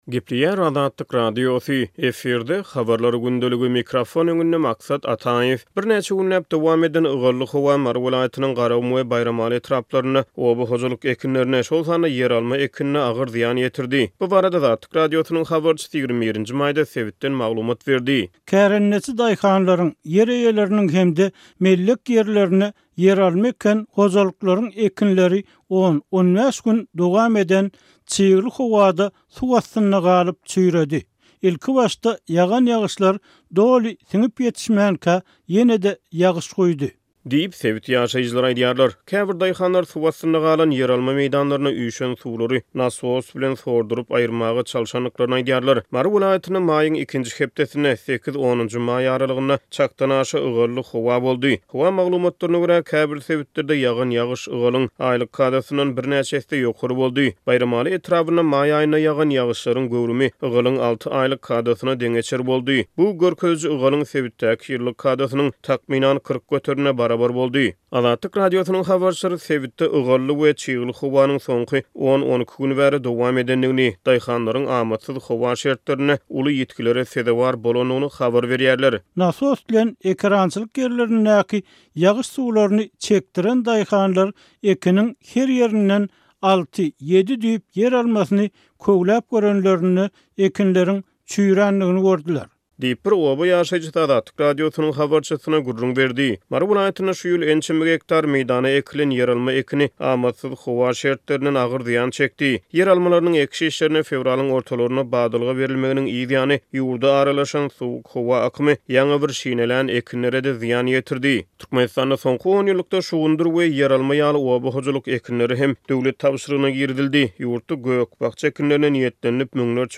Birnäçe günläp dowam eden ygally howa Mary welaýatynyň Garagum we Baýramaly etraplarynda oba hojalyk ekinlerine, şol sanda ýeralma ekinine agyr zyýan ýetirdi. Bu barada Azatlyk Radiosynyň habarçysy 21-nji maýda sebitden maglumat berdi.